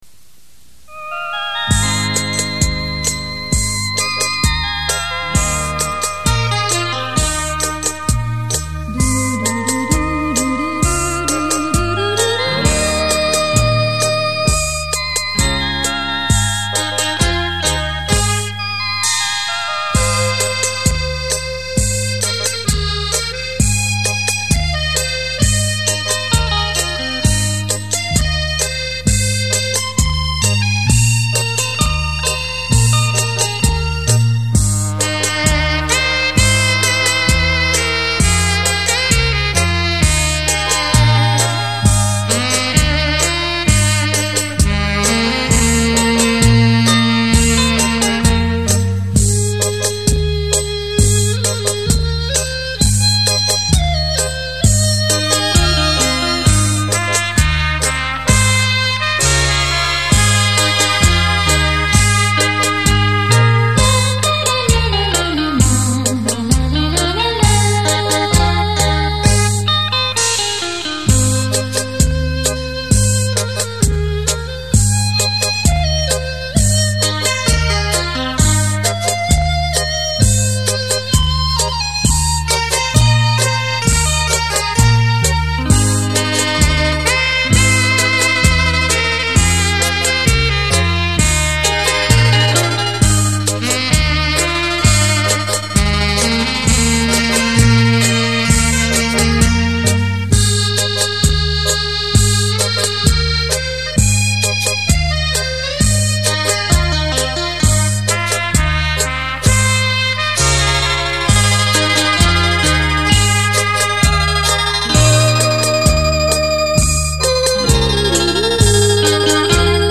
本专辑是一张根据日本地方民谣小曲改编的情调轻音乐唱片。
出节奏鲜明、曲调逶婉、缠绵而略带伤感的情绪。